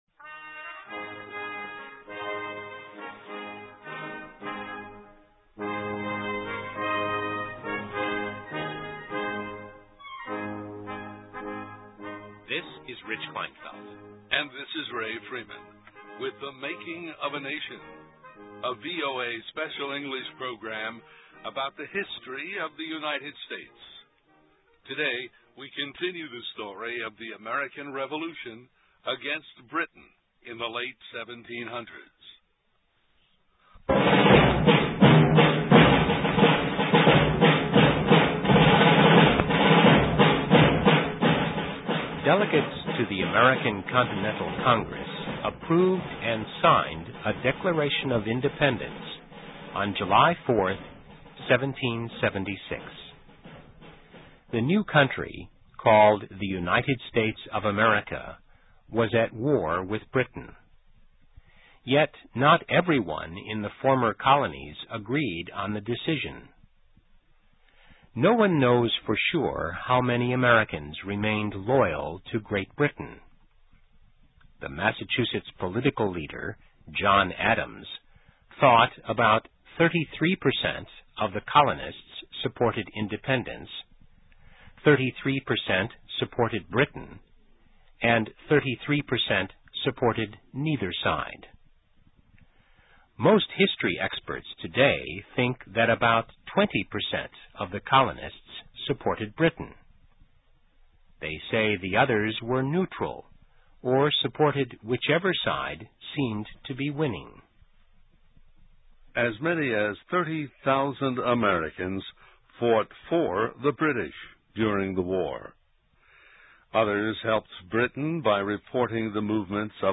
American History: How the Revolution Against Britain Divided Families and Friends (VOA Special English 2007-12-26)
Listen and Read Along - Text with Audio - For ESL Students - For Learning English